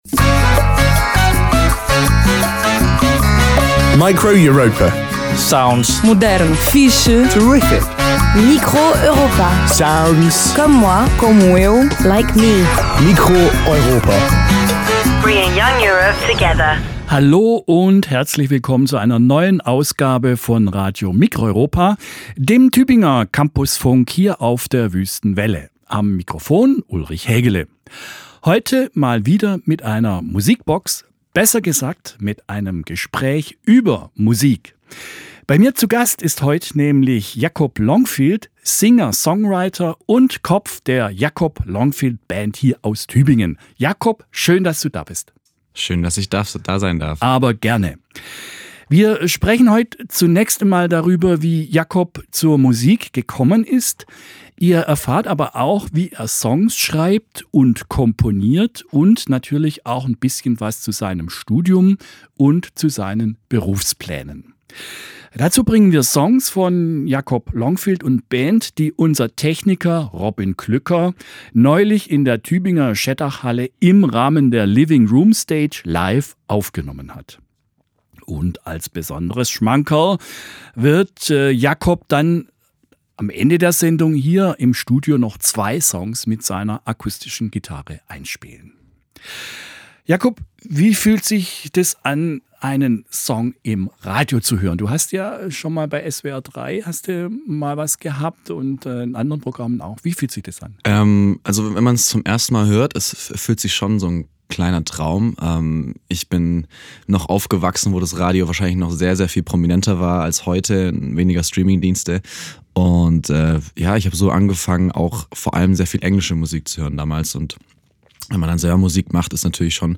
Klassifikation: Musik
Live-Aufzeichnung, geschnitten